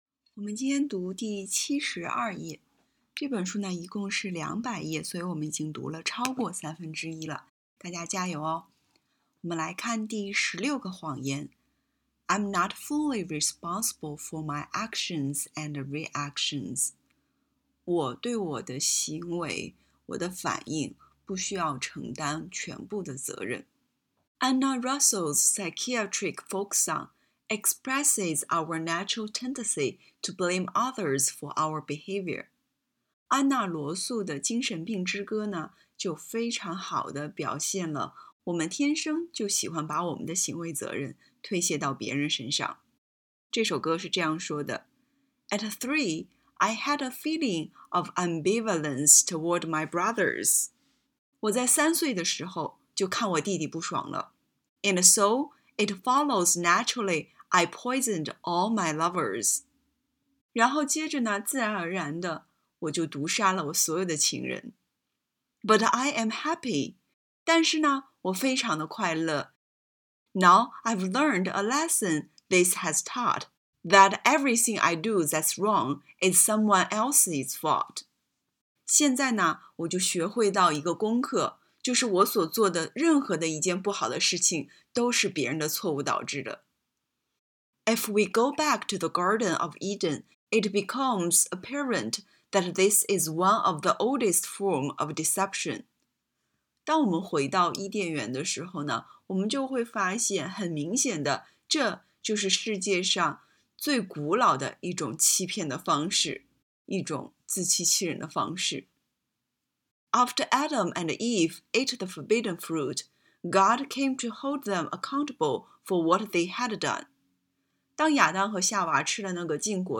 为你讲一本英文书《女人们所相信的谎言》请点击音频，每句英文后有翻译。